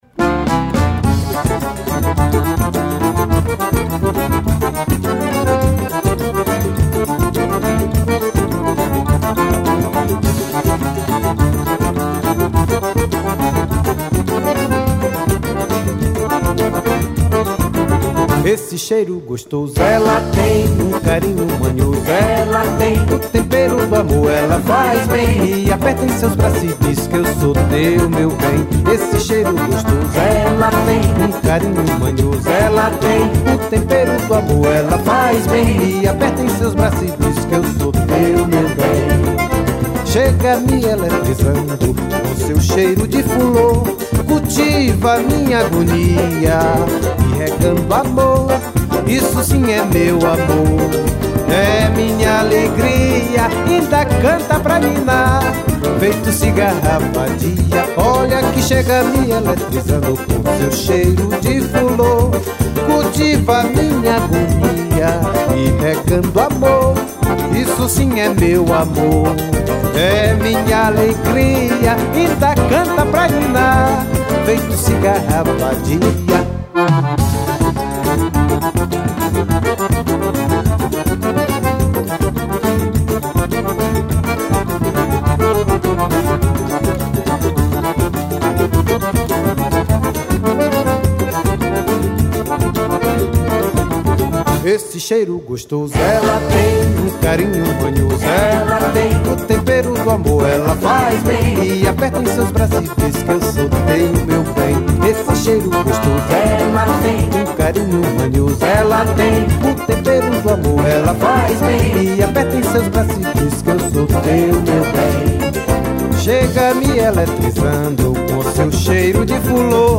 1069   02:51:00   Faixa:     Forró